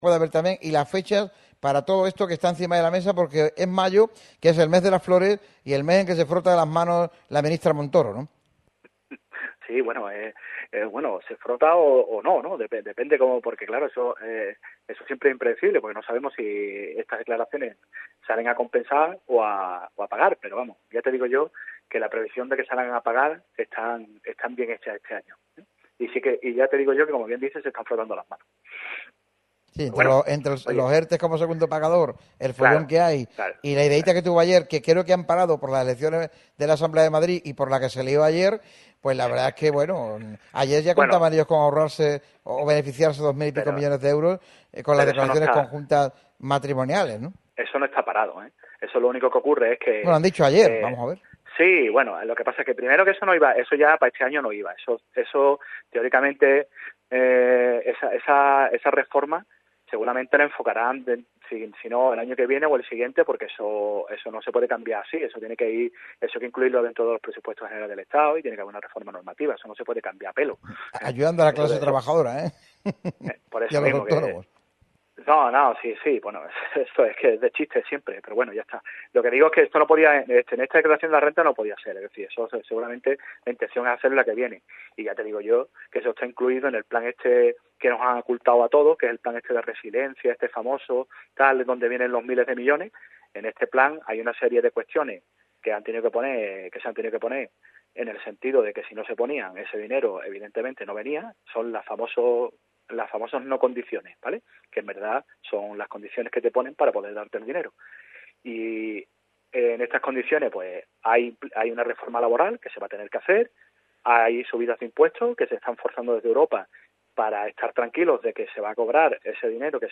habló -como cada lunes- en los micrófonos de Radio MARCA Málaga.